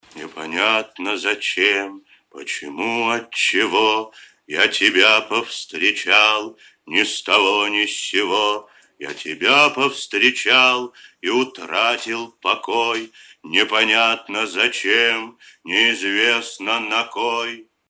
• Качество: 256, Stereo
из фильмов